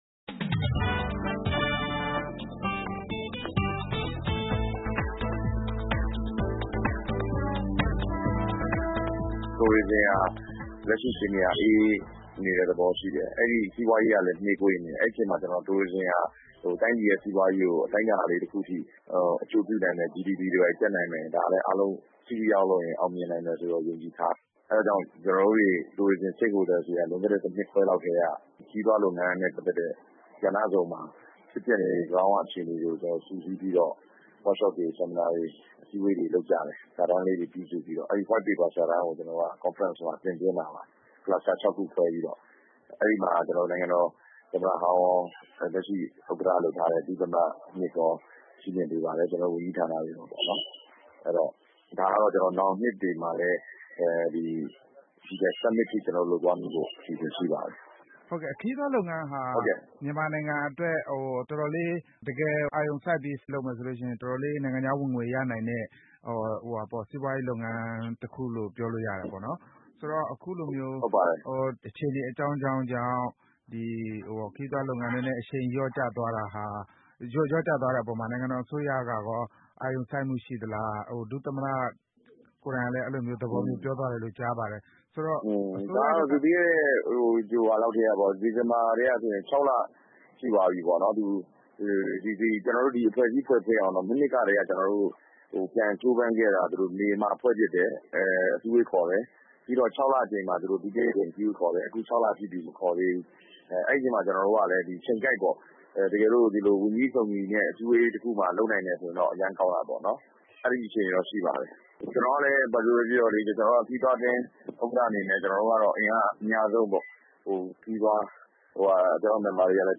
ဆက်သွယ် မေးမြန်းထားတာကို ဒီ သီတင်းပတ်ရဲ့ စီးပွားရေးကဏ္ဍမှာ